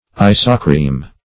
Search Result for " isocryme" : The Collaborative International Dictionary of English v.0.48: Isocryme \I"so*cryme\, n. [Iso- + Gr.